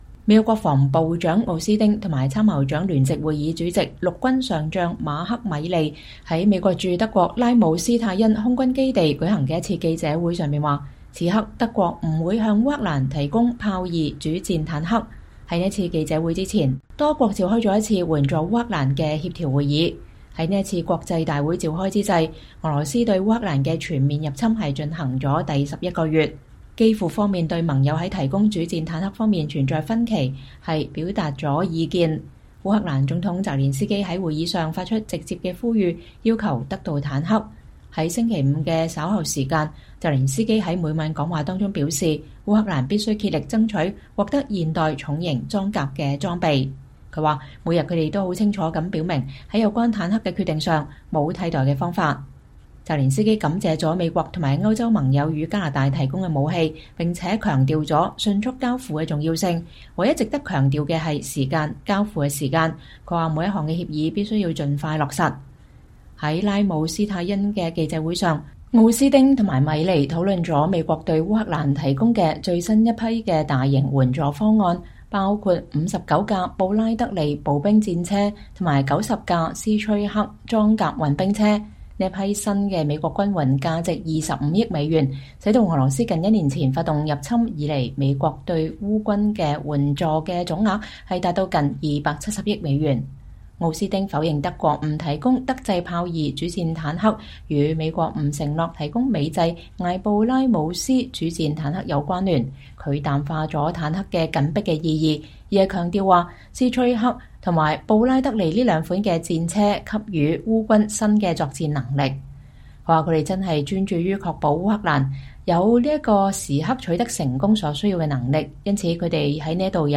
美國國防部長勞埃德·奧斯汀(Lloyd Austin)和參謀長聯席會議主席、陸軍上將馬克·A·米利(Mark A. Milley)在美國駐德國拉姆施泰因空軍基地舉行的一次記者會上說，此刻德國不會向烏克蘭提供豹2(Leopard 2)主戰坦克。